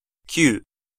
Prononciation
Synonymes nouveau inconnu Prononciation FR: IPA: [nœf] France (Paris): IPA: /nœf/ Accent inconnu: IPA: /nø/ IPA: [œ̃ ʃɑɔ̯ʁ nø] IPA: /nœ.v‿ɑ̃/ IPA: /nœ.v‿œʁ/ IPA: /nœ.f‿ɔm/ IPA: /nœ.v‿ɔm/ IPA: /nœ.f‿otʁ/ IPA: /nœ.v‿otʁ/